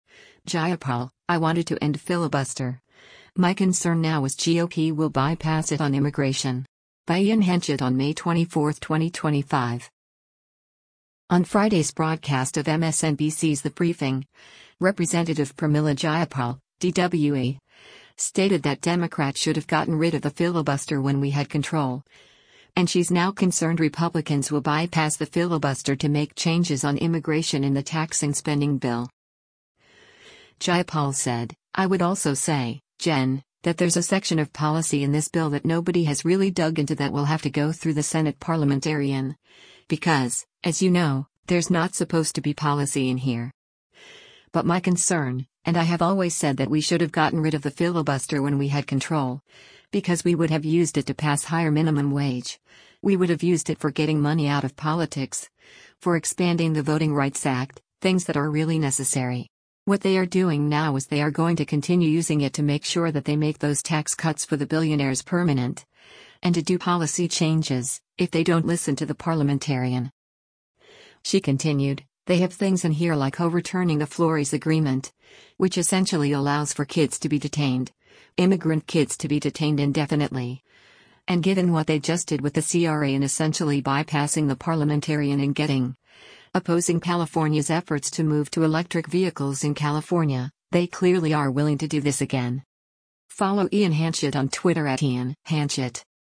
On Friday’s broadcast of MSNBC’s “The Briefing,” Rep. Pramila Jayapal (D-WA) stated that Democrats “should have gotten rid of the filibuster when we had control,” and she’s now concerned Republicans will bypass the filibuster to make changes on immigration in the tax and spending bill.